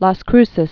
(läs krsĭs)